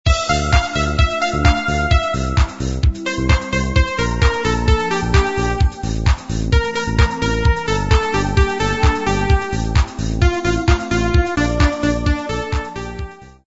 Послушать пример мелодии
• Пример мелодии содержит искажения (писк).